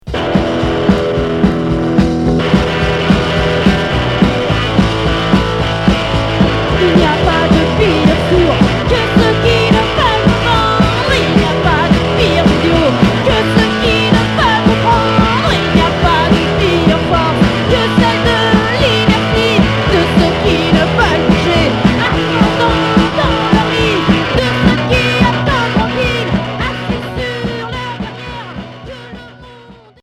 Anarcho punk